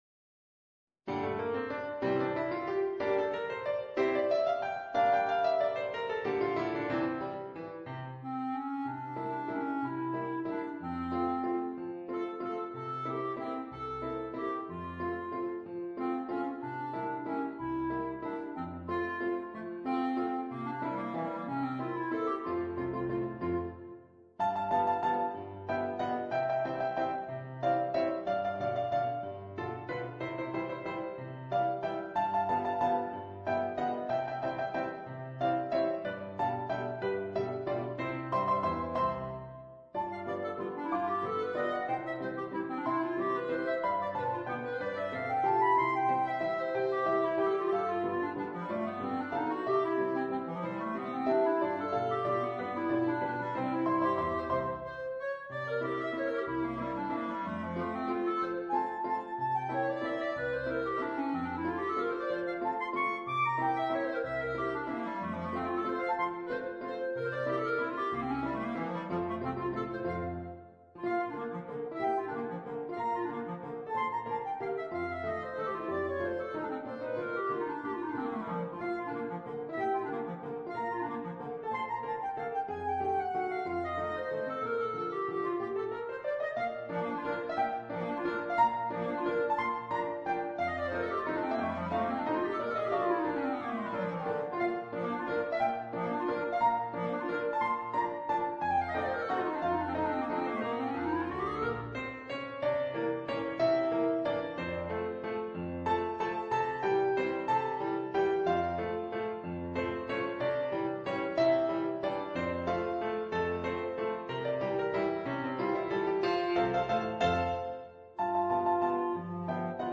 in una versione elaborata per clarinetto e pianoforte.